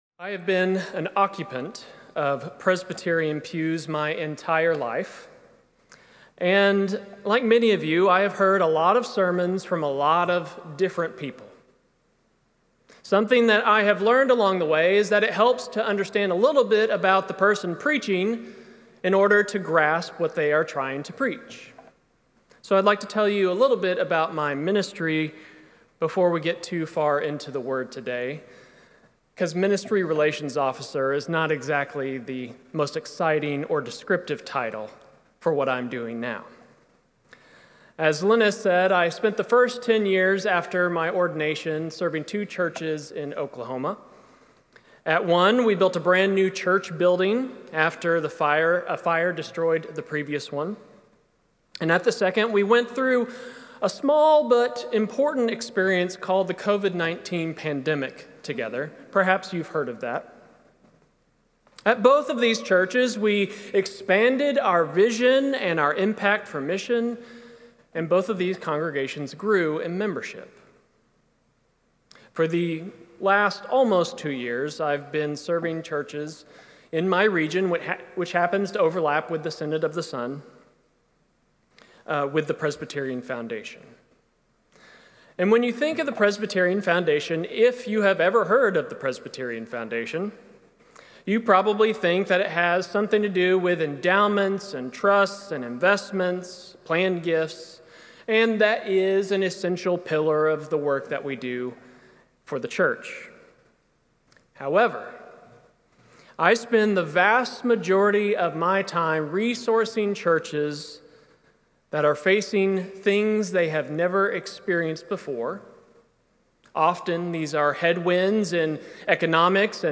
Sermon+9-7-25+This+One.mp3